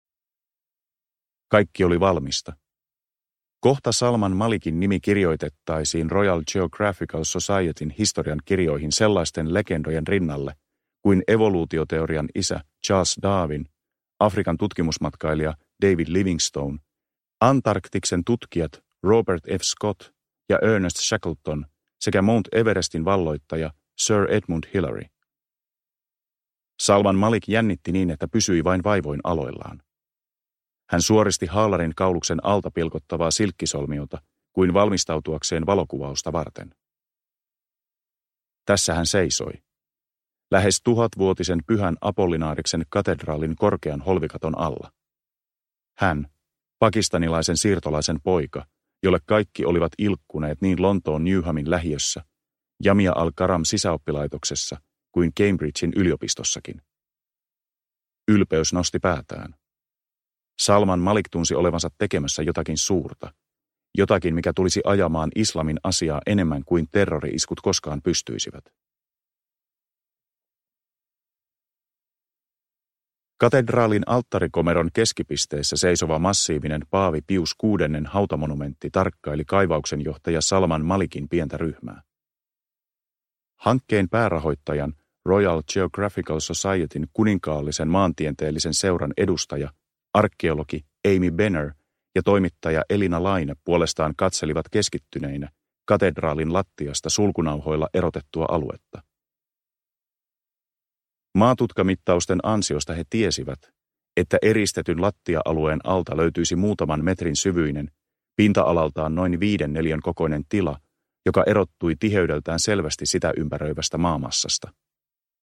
Jumalten sota – Ljudbok – Laddas ner